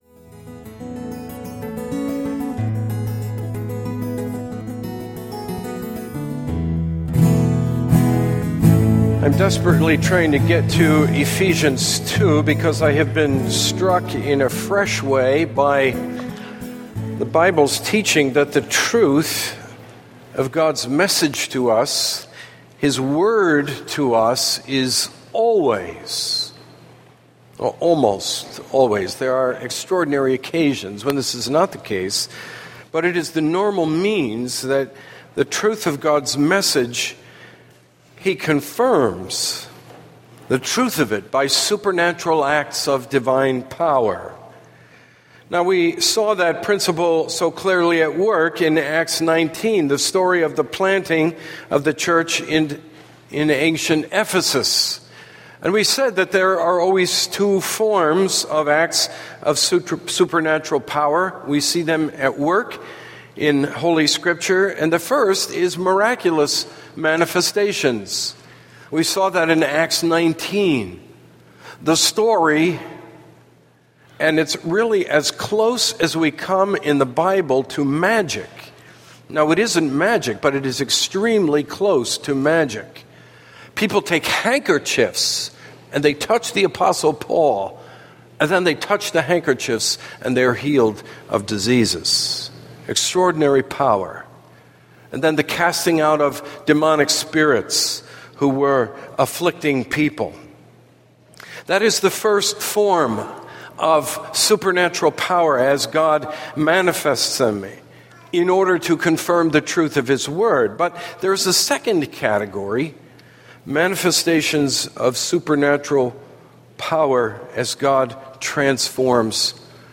Sermon Download